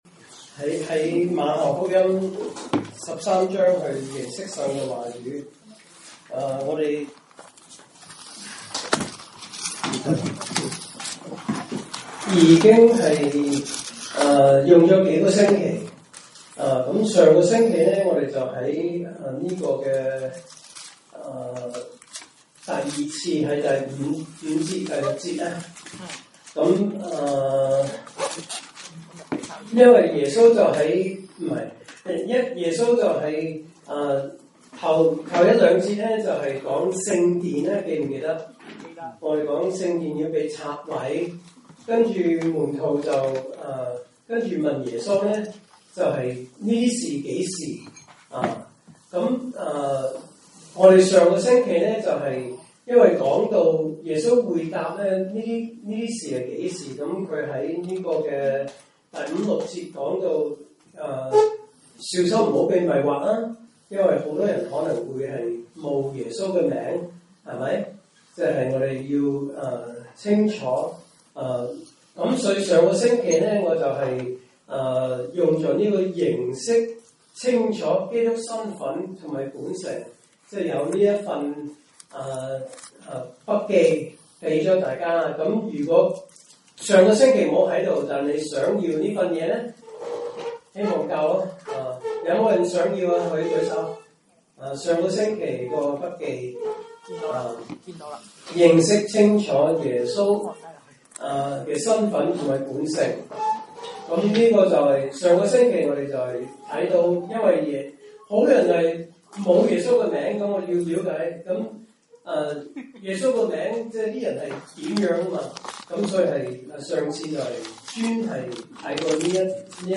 證道信息